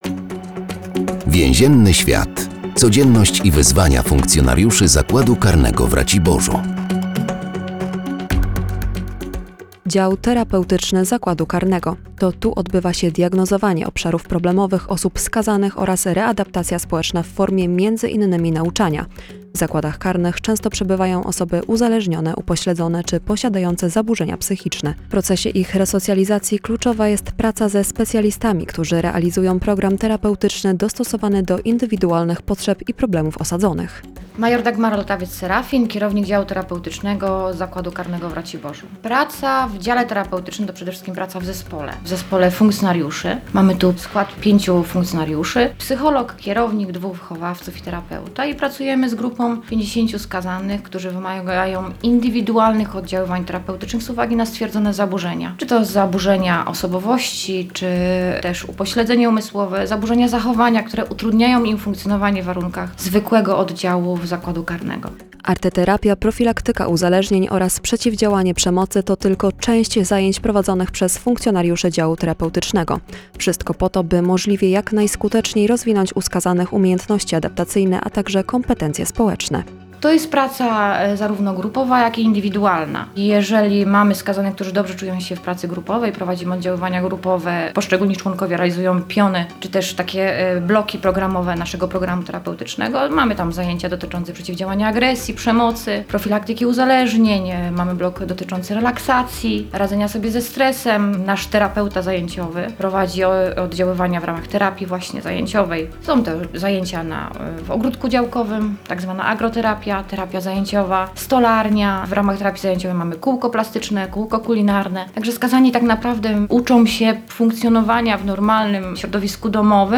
W każdym odcinku audycji “Więzienny świat” funkcjonariusze zakładu karnego w Raciborzu opowiadają o specyfice swojej pracy.